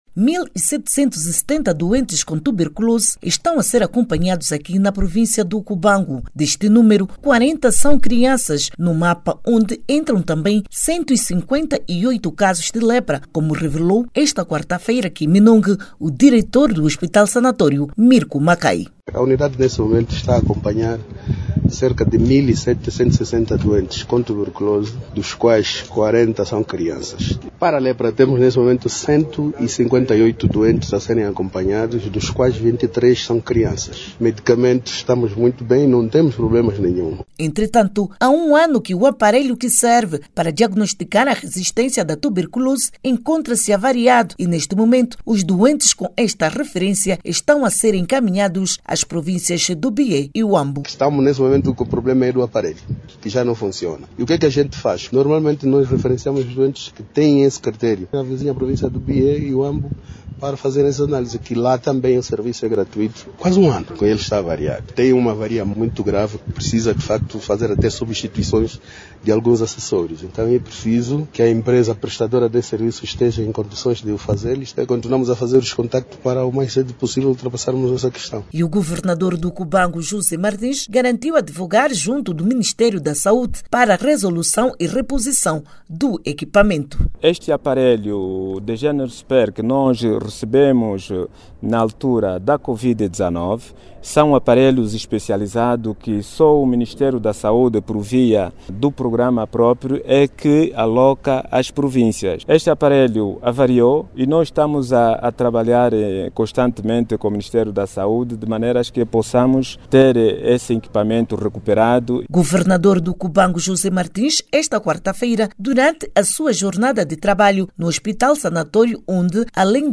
O governador do Cubango, José Martins, diz estar a trabalhar com o Ministério da Saúde para se resolver o problema. Clique no áudio abaixo e ouça a reportagem